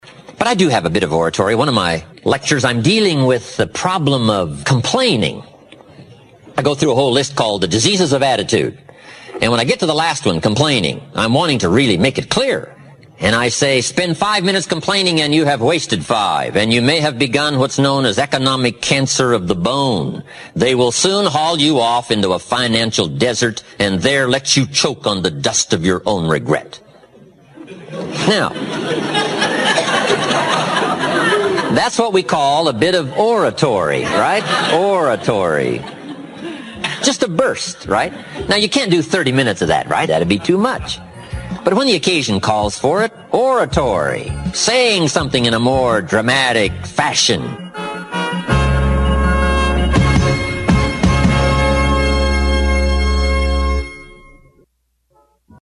רוב ההקלטות ב-"תחנת הרדיו הפרטית" בוצעו במכשיר הסמארטפון והועלו לכאן ללא כל עריכה, וכך גם אתה יכול להקליט את המסר שלך, ללחוץ עוד קליק או 2, ולשדר את עצמך והמסר שלך - לעולם!
JimRohan-Oratory-Complaining.mp3